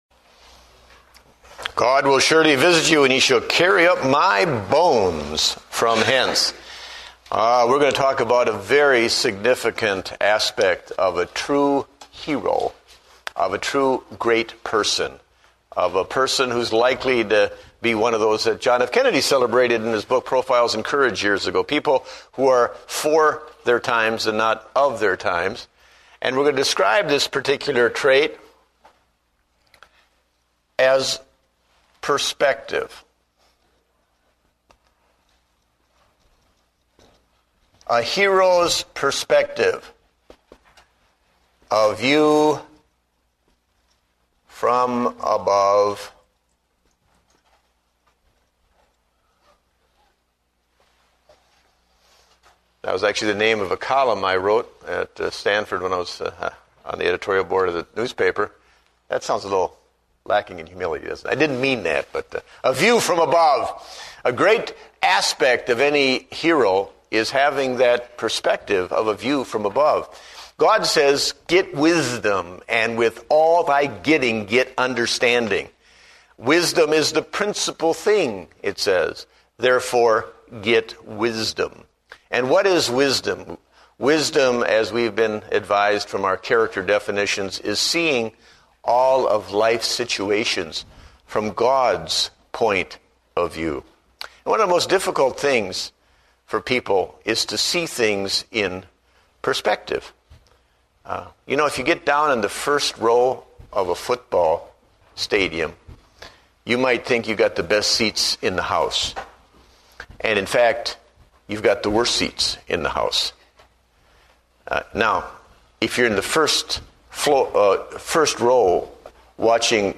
Date: May 31, 2009 (Adult Sunday School)